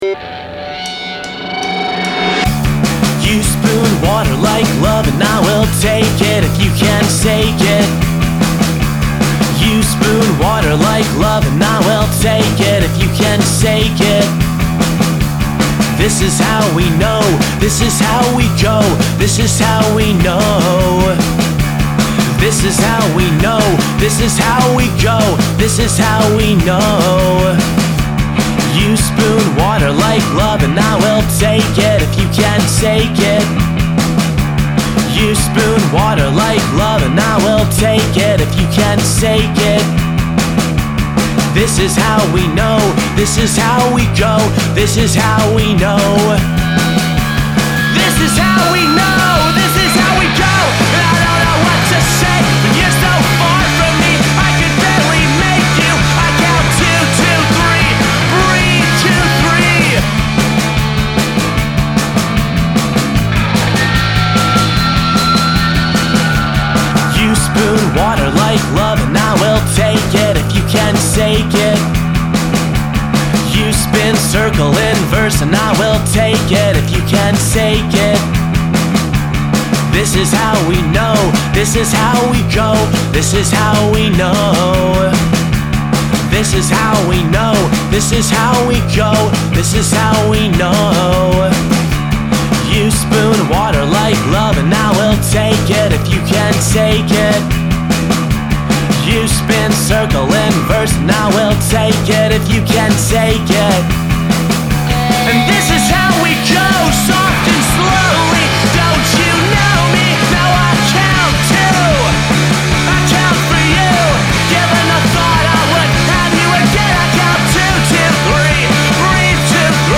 indie-punk